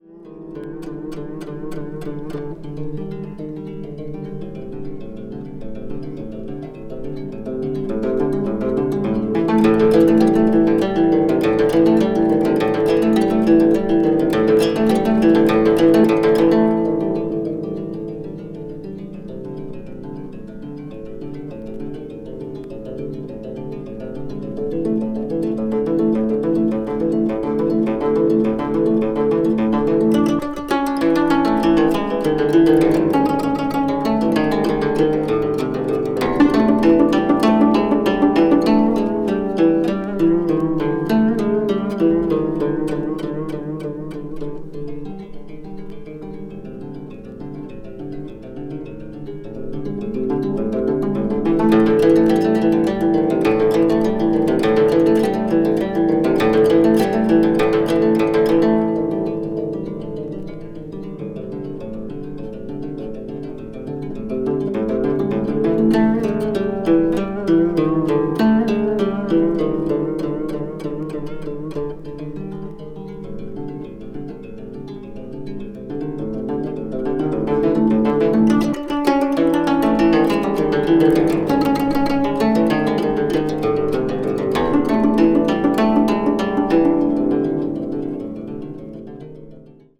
media : EX/EX(some slightly noises.)